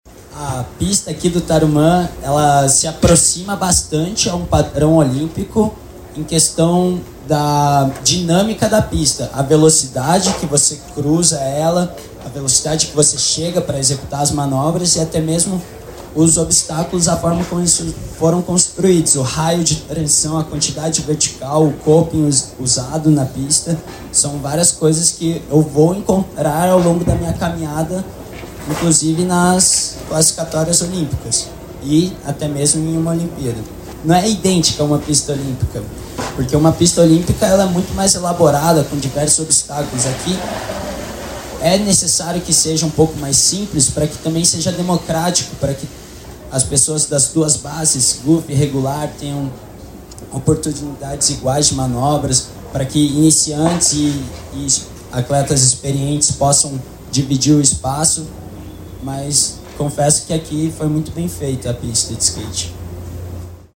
Em uma entrevista coletiva nesta quinta (24), Akio, o “Japinha”, que conquistou a medalha de bronze nos Jogos Olímpicos de Paris, elogiou a pista.